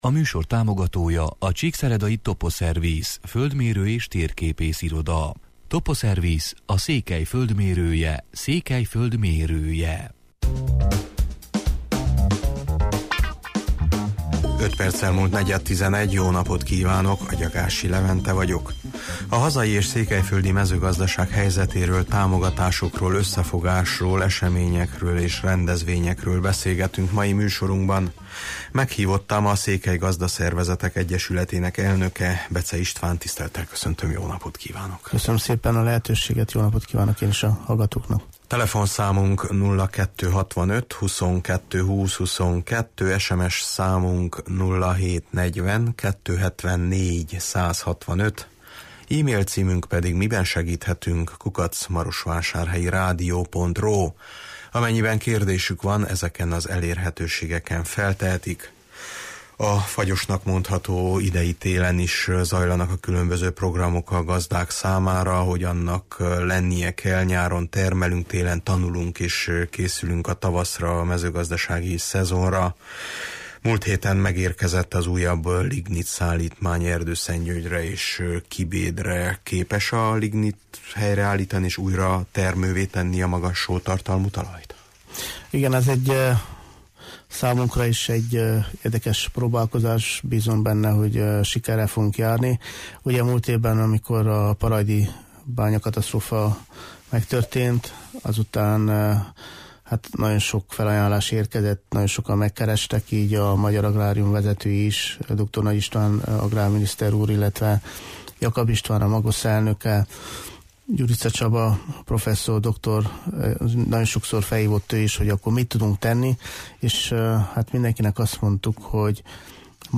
A hazai és székelyföldi mezőgazdaság helyzetéről, támogatásokról, összefogásról, eseményekről és képzésekről beszélgetünk mai műsorunkban.